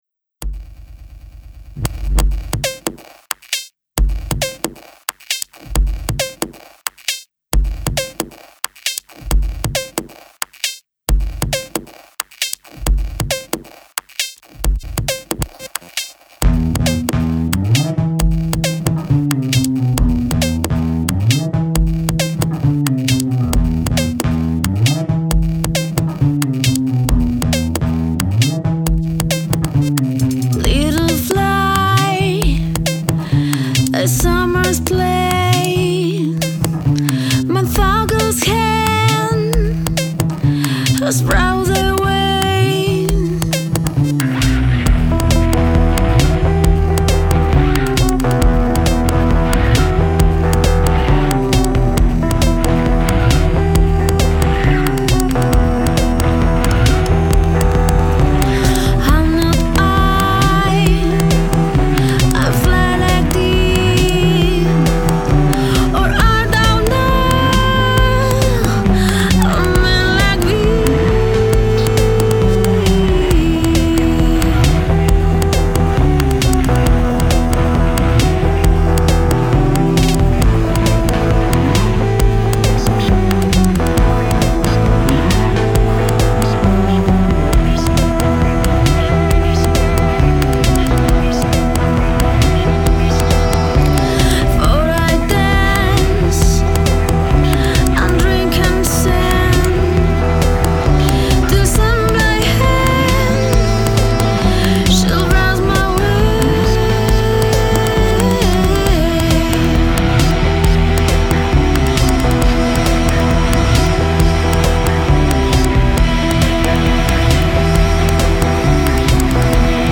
bass, guitars, electrönics, prögramming, lööps, nöises
Genre Rock